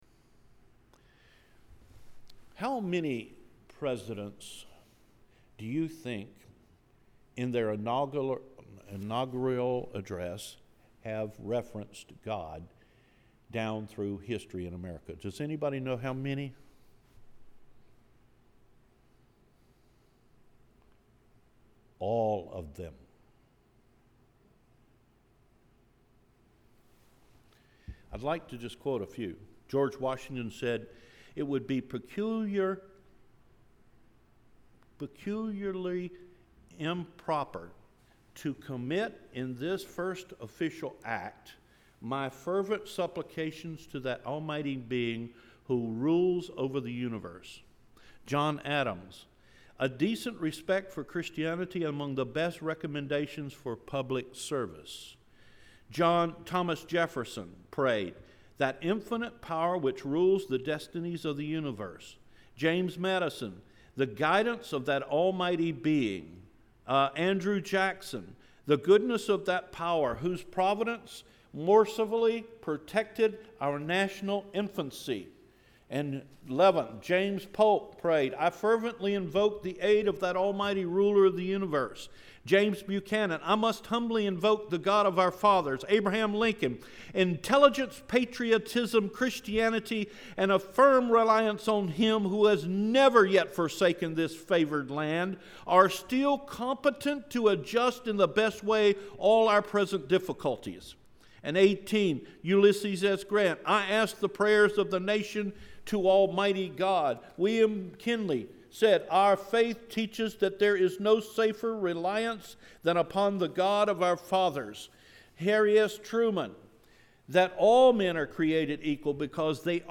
What Our Country Was Built On – July 2 Sermon